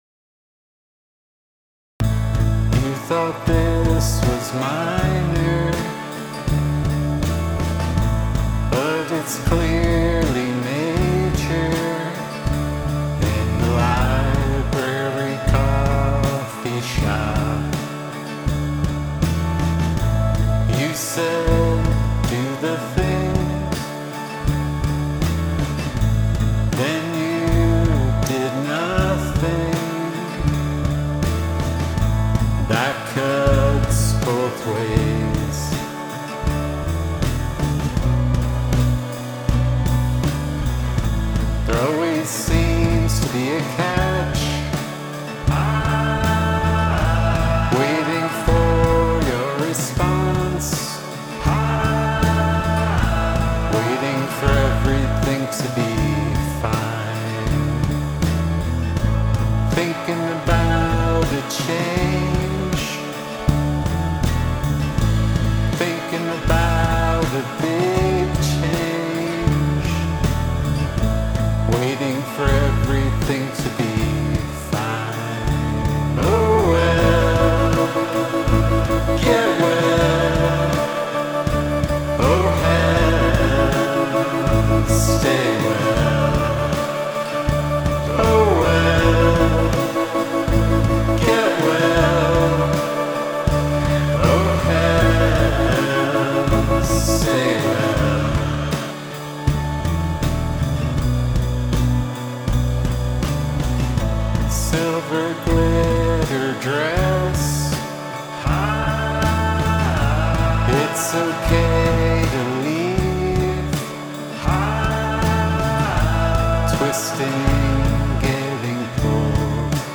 Is the Mix Muddy?
I tweaked it some - here's a remix.